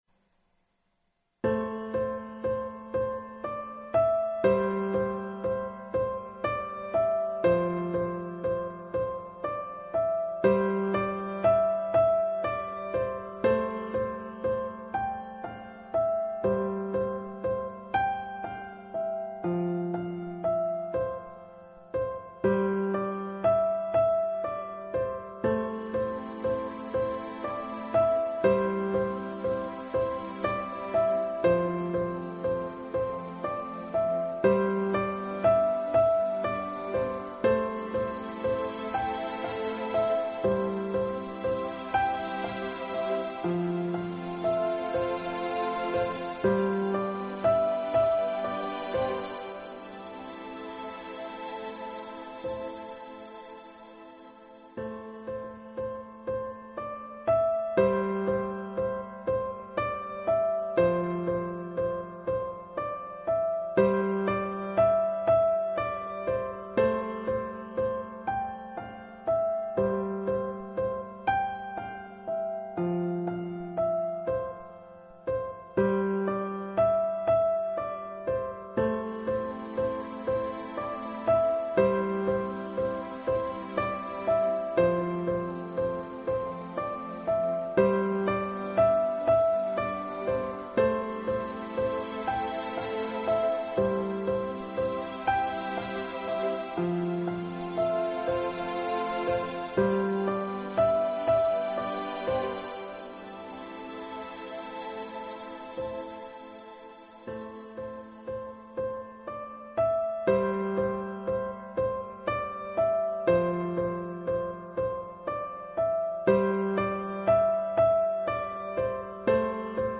Fortepyano - Грустная мелодия о любви
Fortepyano-_-Grustnaya-melodiya-o-ljybvi.mp3